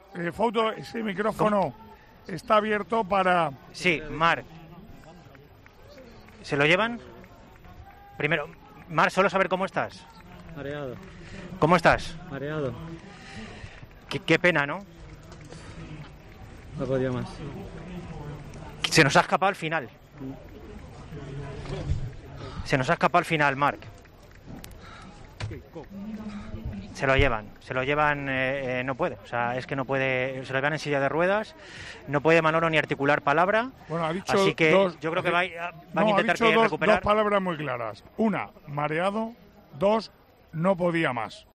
Marc Tur, incapaz de articular palabra tras cruzar la línea de meta: "No podía más"